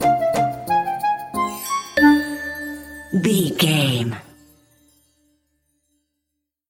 Uplifting
Aeolian/Minor
Slow
flute
oboe
piano
percussion
silly
circus
goofy
comical
cheerful
perky
Light hearted
quirky